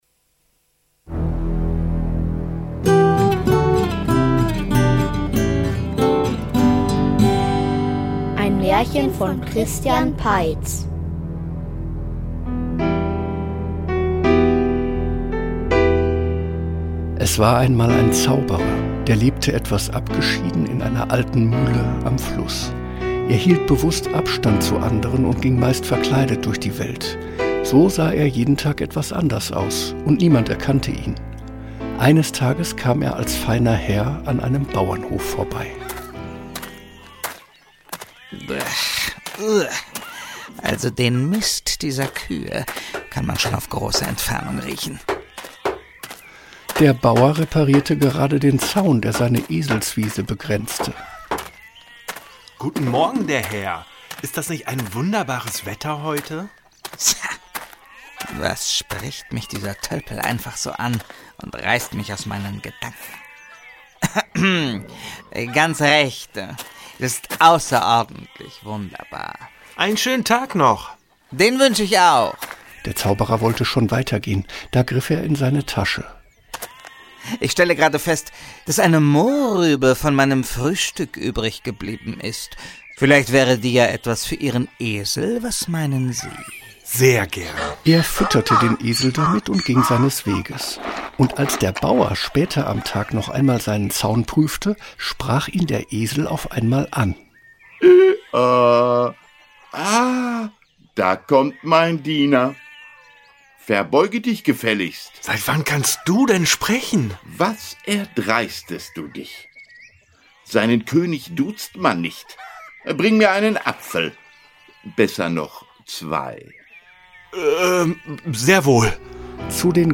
Kaninchenzauber --- Märchenhörspiel #66 ~ Märchen-Hörspiele Podcast